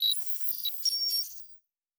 pgs/Assets/Audio/Sci-Fi Sounds/Electric/Data Calculating 2_4.wav at master
Data Calculating 2_4.wav